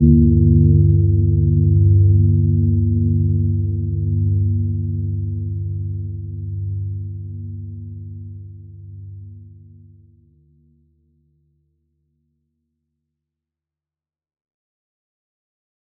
Gentle-Metallic-2-G2-mf.wav